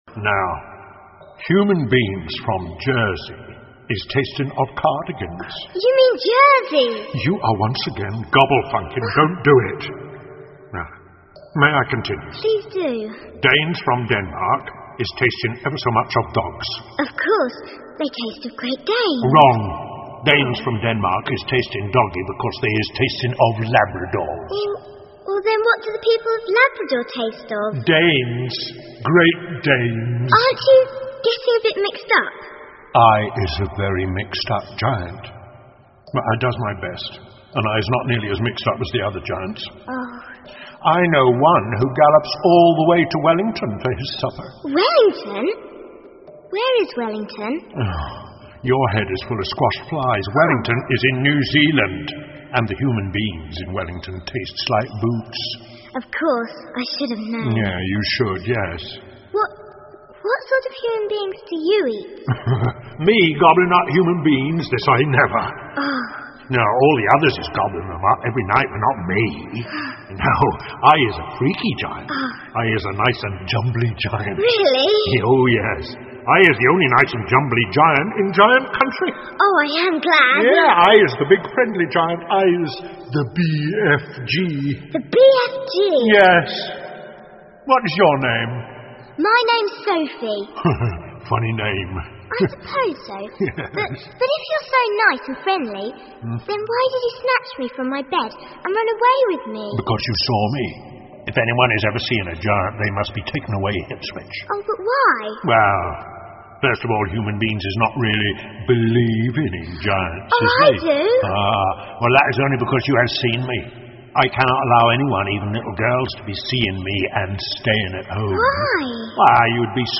The BFG 好心眼儿巨人 儿童广播剧 3 听力文件下载—在线英语听力室